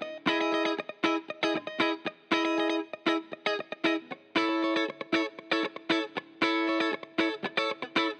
04 Guitar PT2.wav